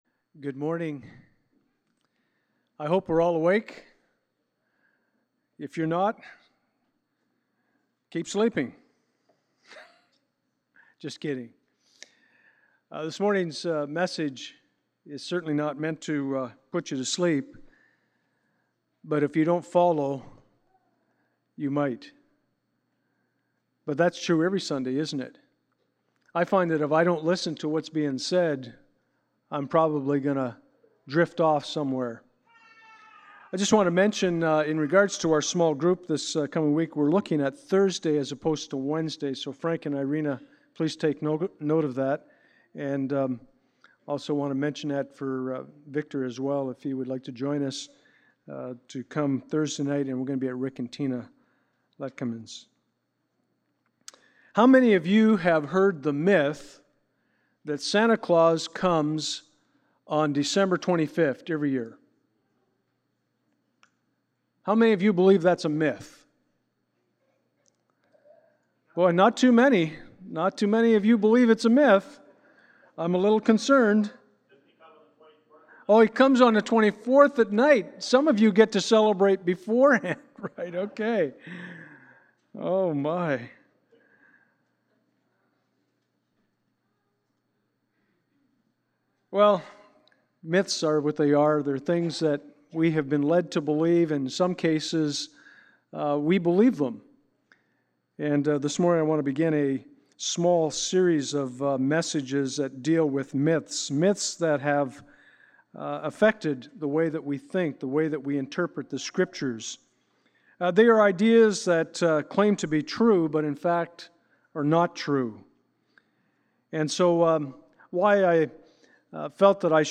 Passage: Ephesians 2:1-10 Service Type: Sunday Morning